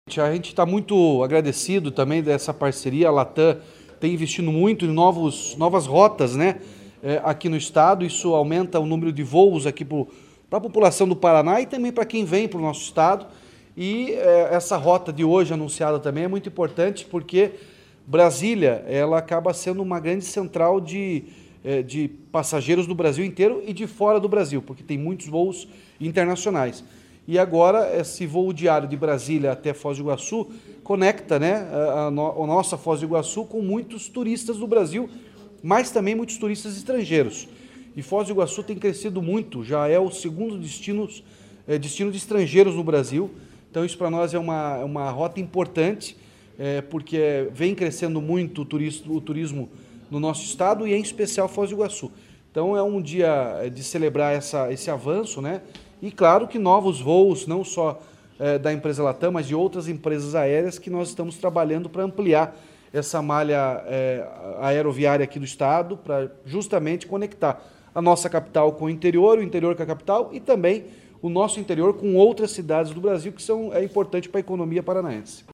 Sonora do governador Ratinho Junior sobre o voo direto entre Foz do Iguaçu e Brasília